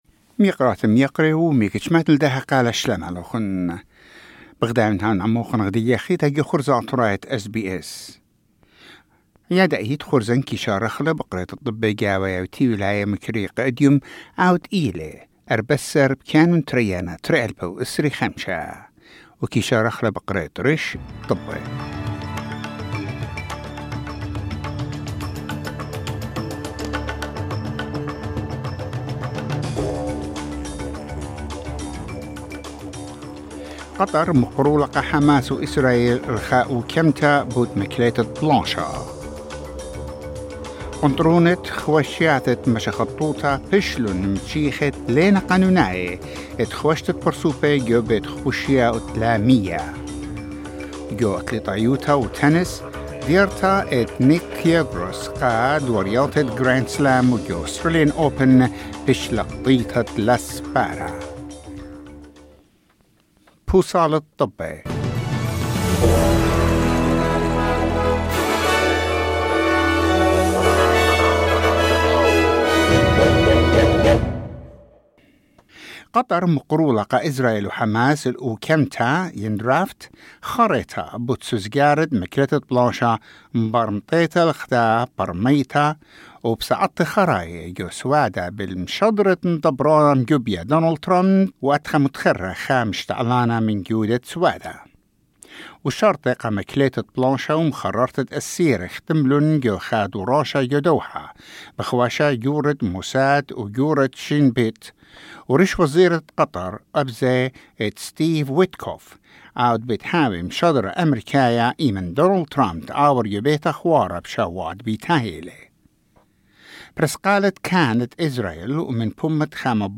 News bulletin: 14 January 2025